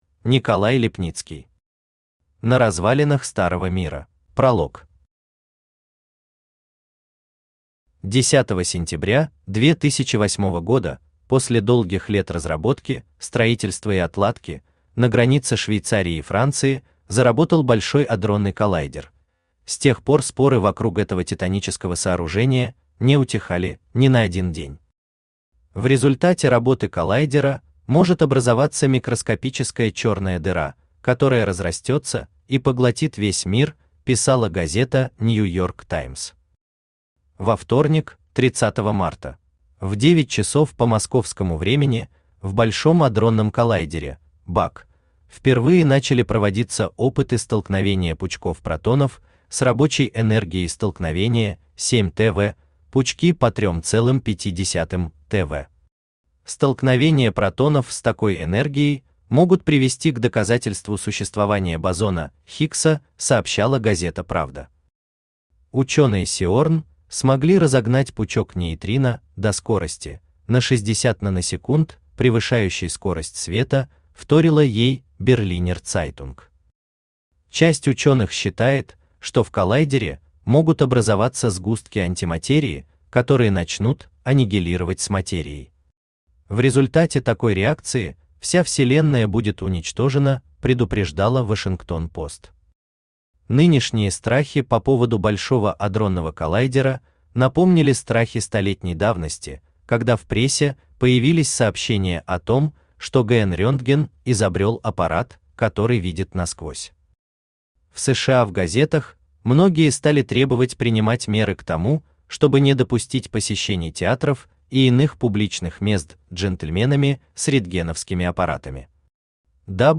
Аудиокнига На развалинах старого мира | Библиотека аудиокниг
Aудиокнига На развалинах старого мира Автор Николай Иванович Липницкий Читает аудиокнигу Авточтец ЛитРес.